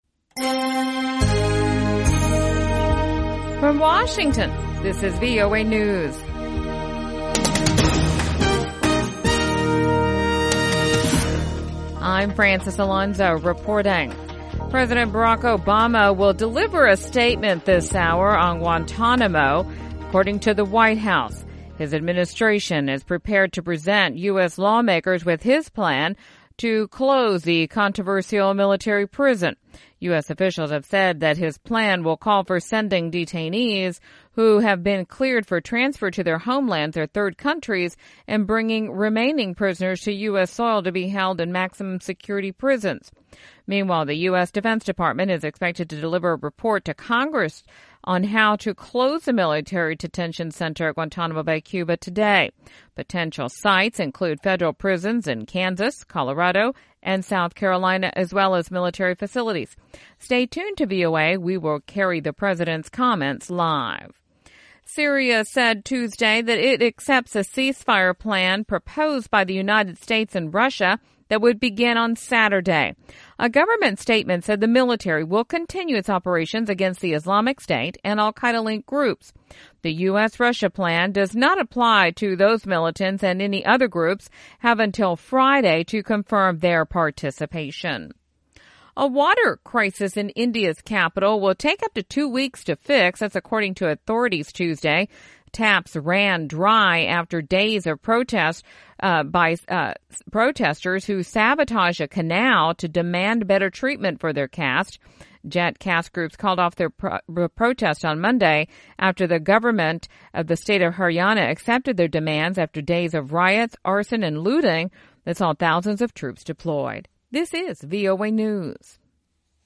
VOA English Newscast 1500 UTC February 23, 2016